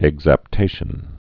(ĕgzăp-tāshən)